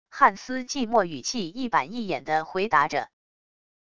汉斯・季默语气一板一眼的回答着wav音频